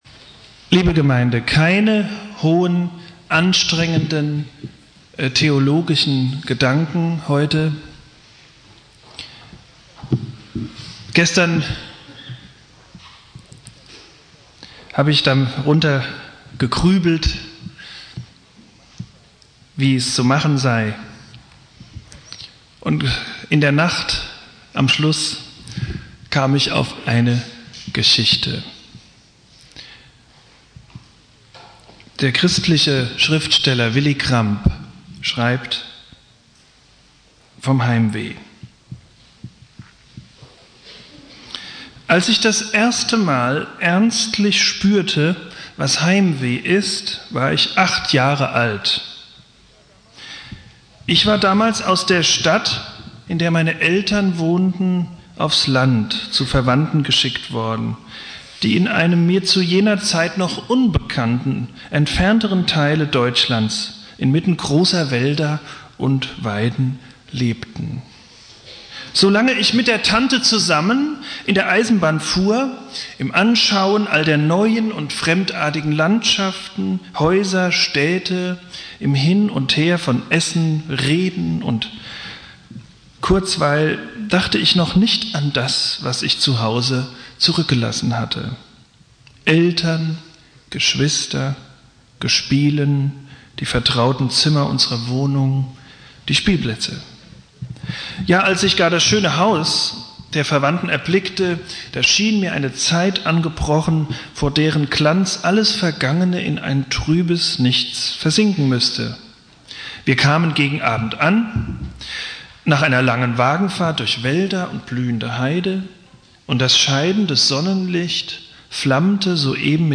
Predigt
Ewigkeitssonntag Prediger